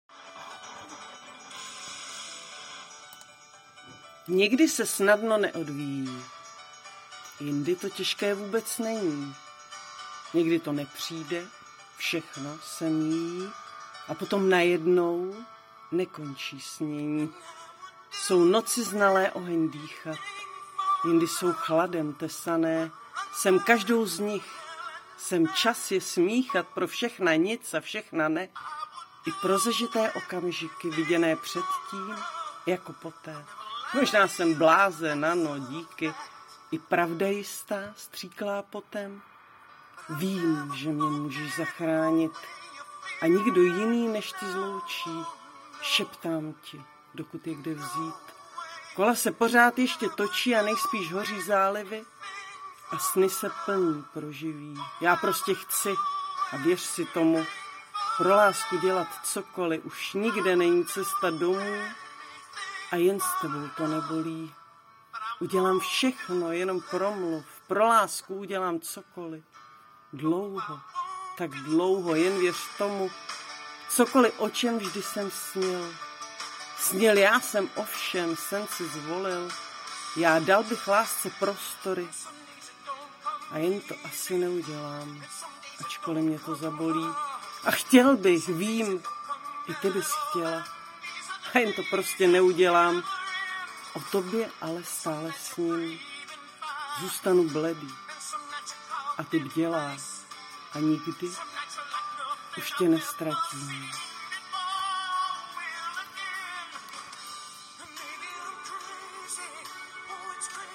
:DD já jsem to jen zkoušela - ten proces - nahrát na telefon, přeposlat - najít program na změnu aac na mp3 a dát (zvládla jsem to sama - bez pomoci - a o to mi šlo)